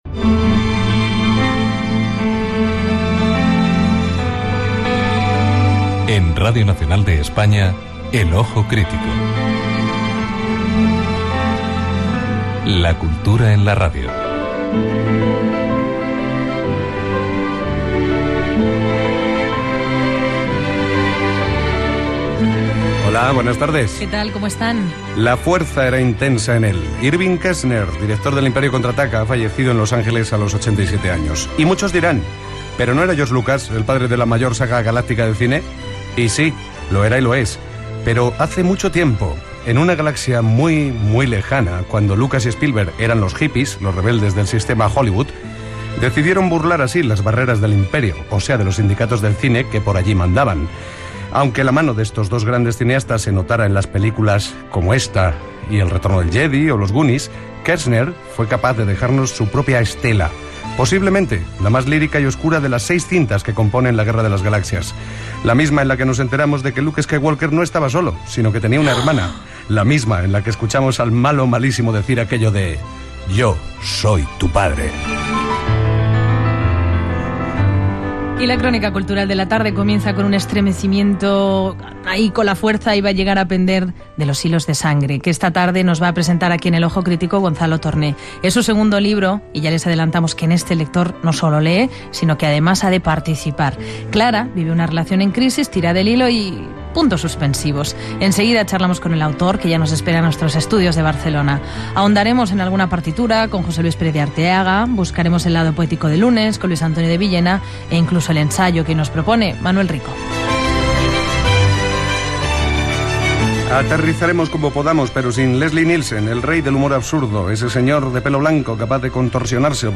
Careta del programa, mort del director de cinema Irvin Kershner
Gènere radiofònic Cultura